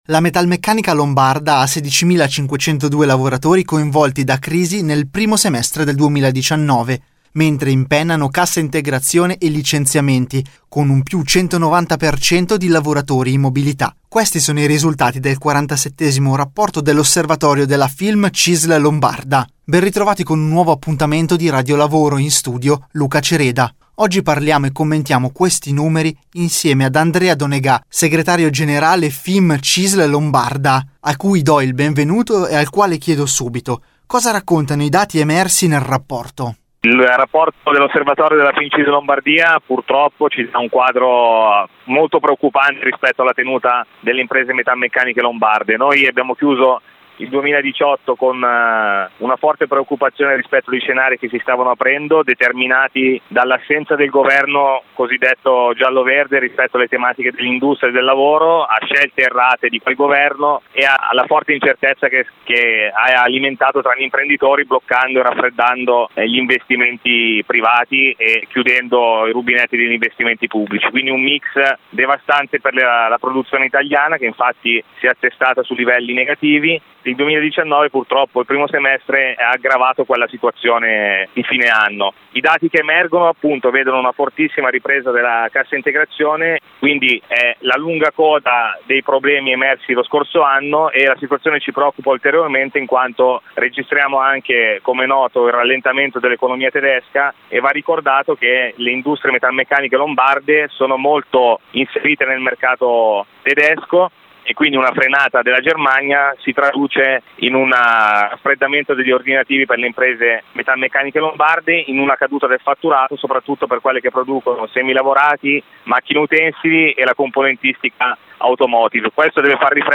Di seguito la puntata del 12 settembre di RadioLavoro, la rubrica d’informazione realizzata in collaborazione con l’ufficio stampa della Cisl Lombardia e in onda tutti i giovedì alle 18.20 su Radio Marconi in replica il venerdì alle 12.20.